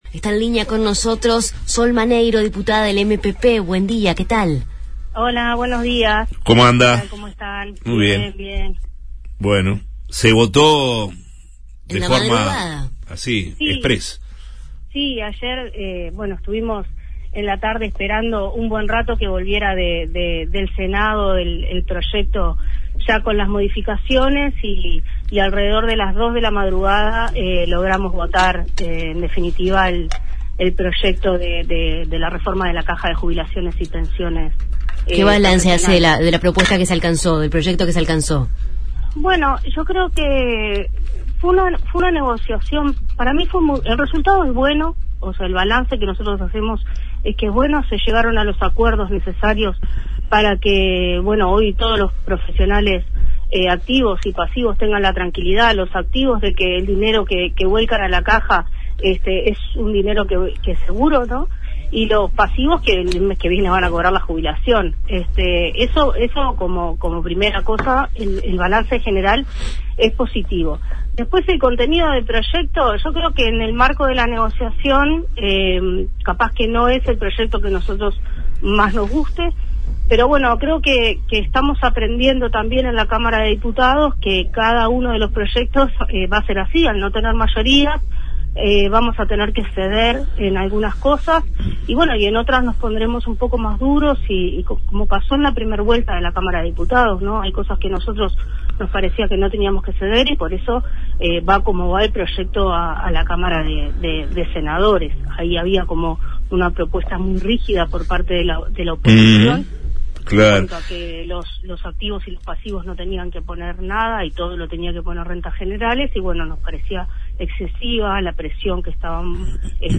Entrevistamos a la diputada Sol Maneiro (Frente Amplio) y a la senadora Graciela Bianchi (Partido Nacional) sobre el acuerdo para la Caja de Profesionales Universitarios
Escuchar a Sol Maneiro: